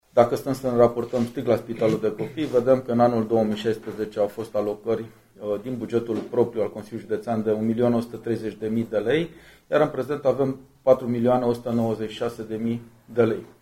Modernizarea UPU a costat 530.000 de lei iar unitatea, care avea o suprafață de 250 de metri pătrați, a ajuns acum la 380 metri pătrați. Președintele Consiliului Județean Brașov, Adrian Veștea: